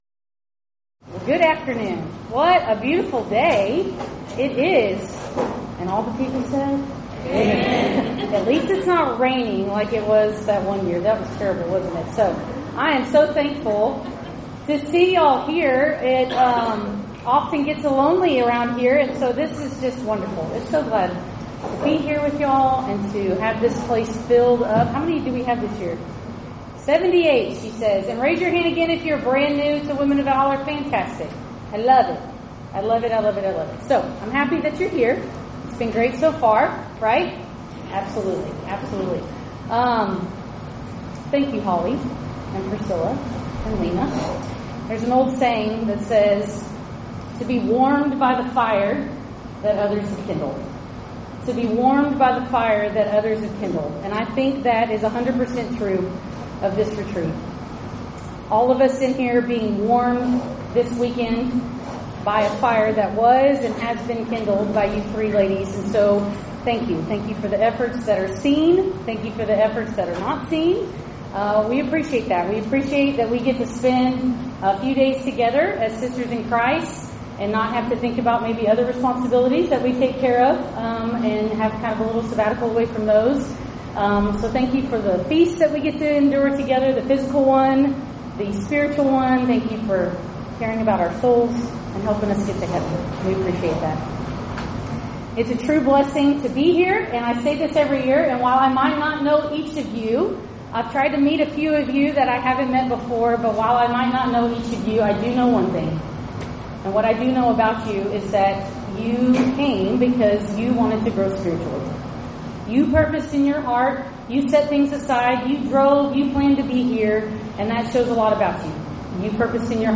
Event: 8th Annual Women of Valor Ladies Retreat
Ladies Sessions